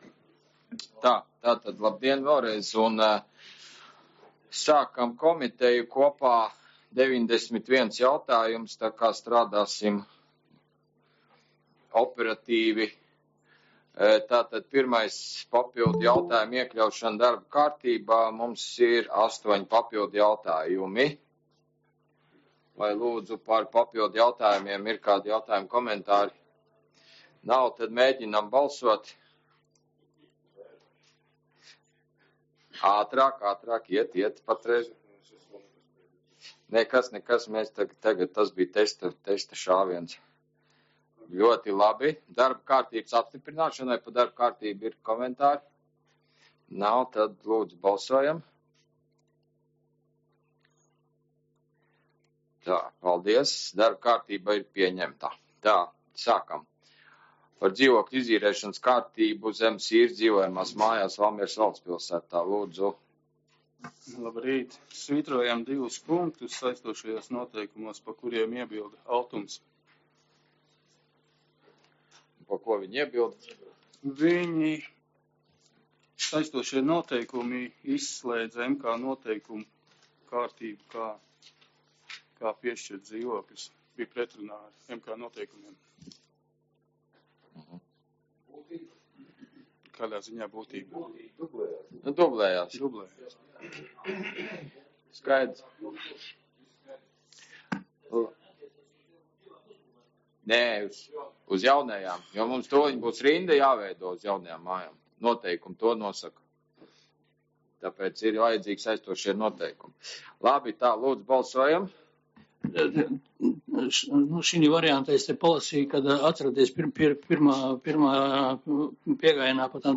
Valmieras novada pašvaldības domes komiteju apvienotā sēde 11.07.2024.
Valmieras novada pašvaldības domes komiteju apvienotā sēde notiks 2024.gada 11.jūlijā, plkst. 9.00 Raiņa ielā 14 (3.stāvā, 301.telpā), Valmierā, Valmieras novadā